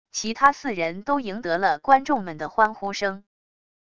其他四人都赢得了观众们的欢呼声wav音频生成系统WAV Audio Player